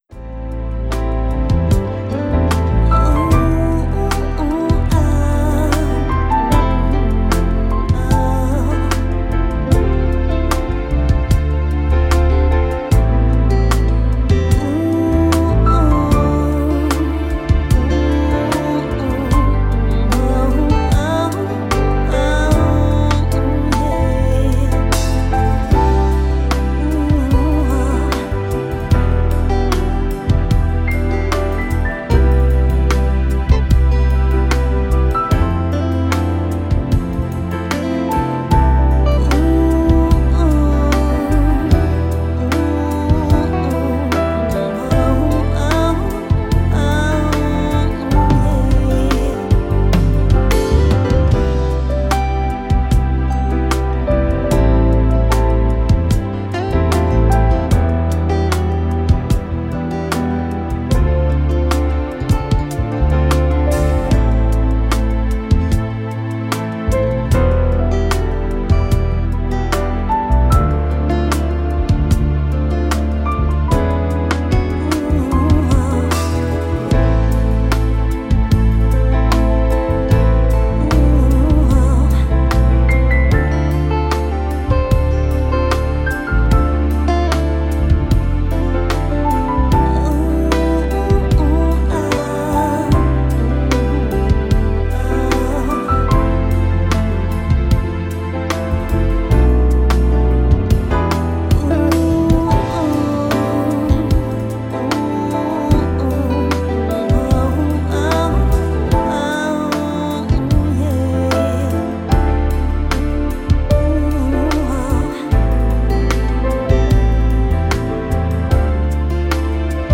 Loving Groove: I was experimenting with an R&B feel and playing around with some vocals, and this song was the result.